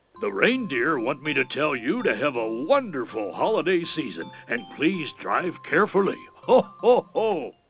Every November, Santa stops by to make professional recordings for ETC's time/temperature/weather service subscribers. He also creates voice mail or information-on-hold system messages for many types of equipment.